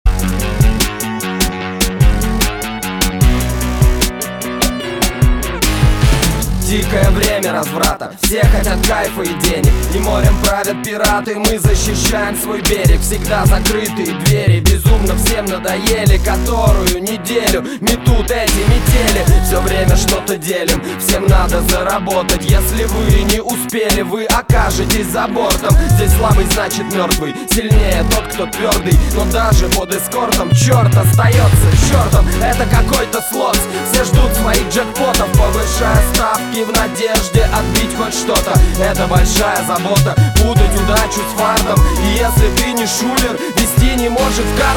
Рэп, Хип-Хоп, R'n'B